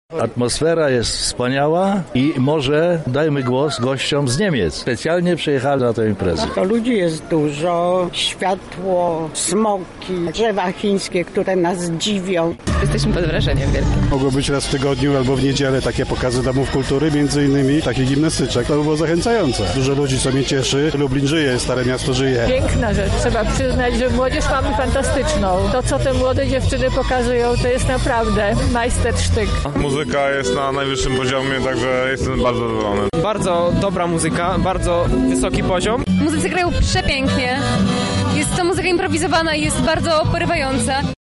O wrażeniach porozmawialiśmy z uczestnikami Nocy Kultury.